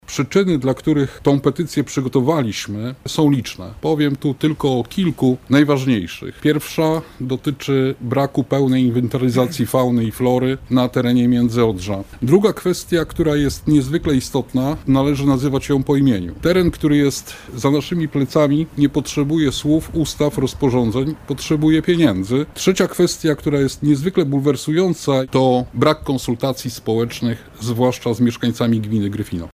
W tej sprawie zorganizowano konferencję na nabrzeżu Odry w Gryfinie – mówi Paweł Nikitiński, radny powiatu gryfińskiego: